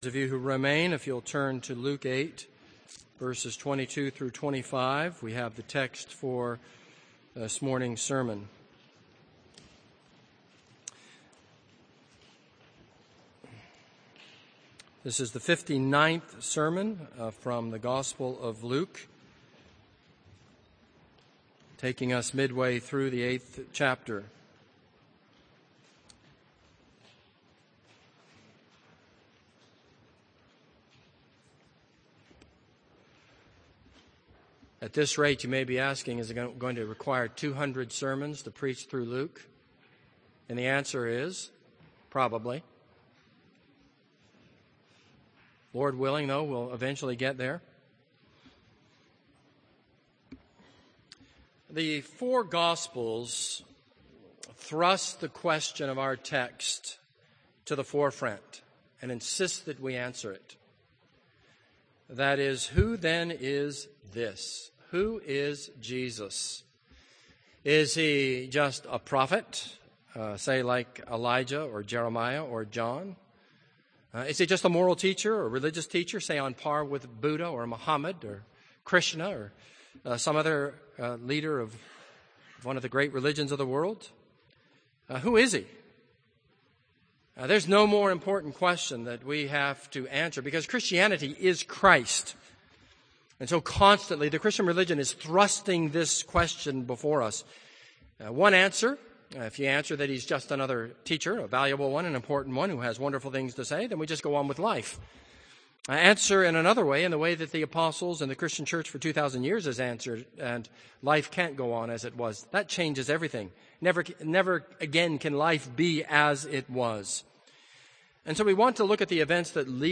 This is a sermon on Luke 8:22-25.